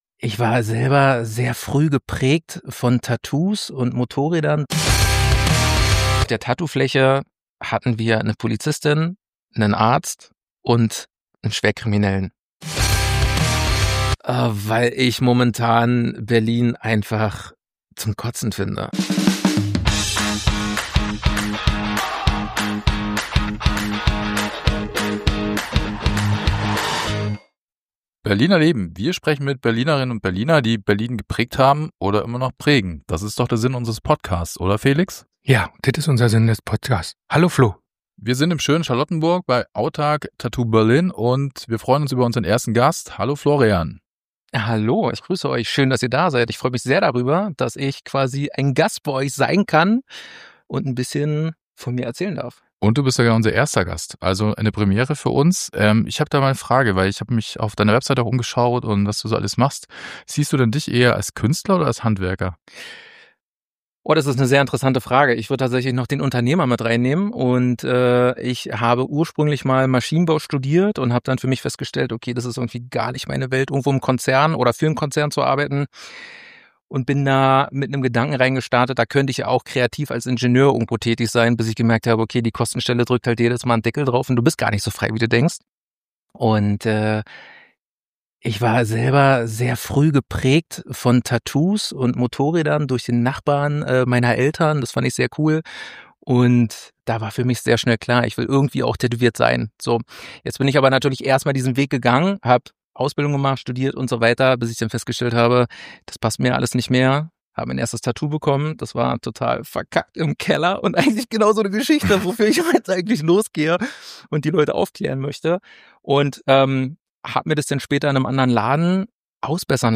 Ein Klartext-Talk über Berliner Leben, Nachbarschaft und die Frage: Wie macht man diese Stadt ein Stück besser?